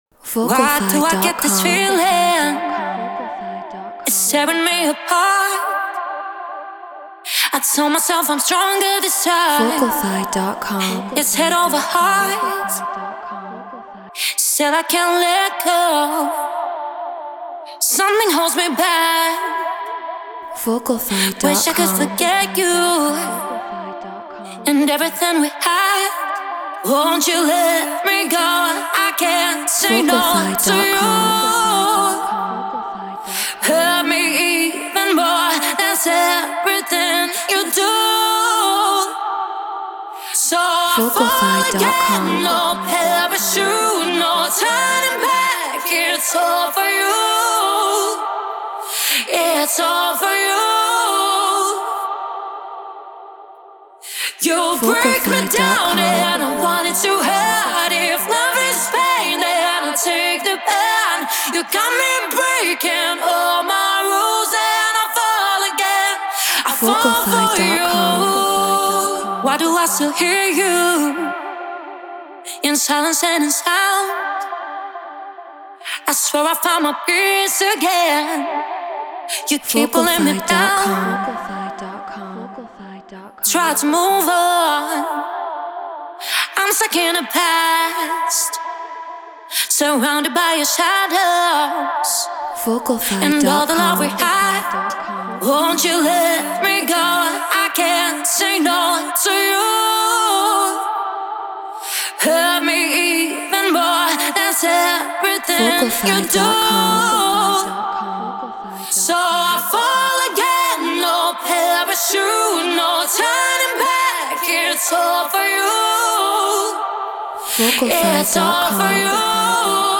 Eurodance 140 BPM Dmin
Treated Room